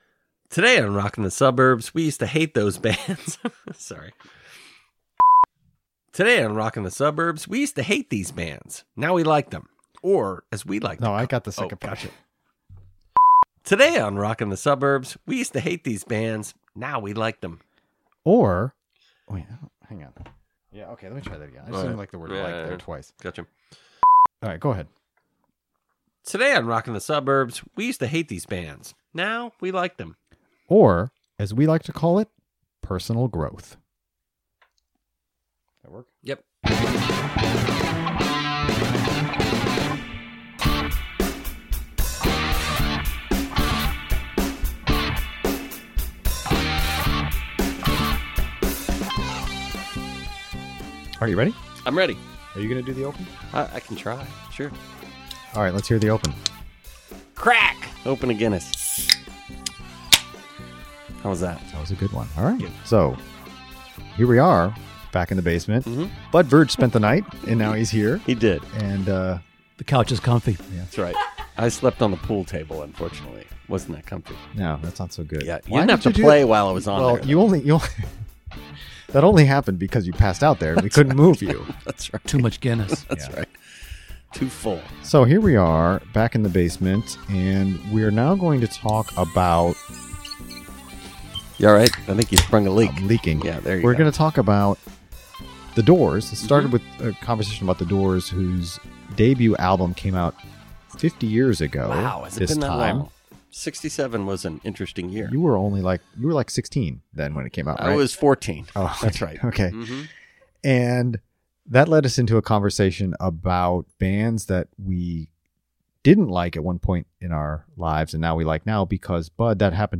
A discussion of bands we once hated, but now we like.